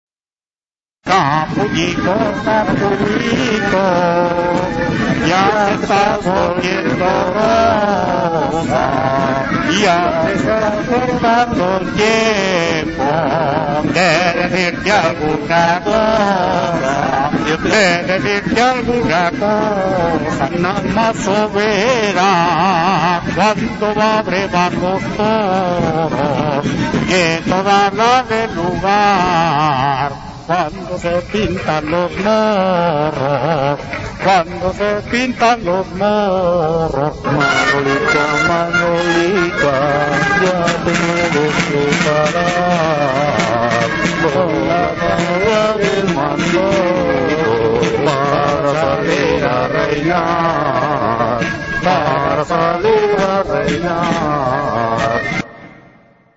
VILLARLUENGO
El baile del reinau.- En el vídeo se representa el reinau por los habitantes del pueblo.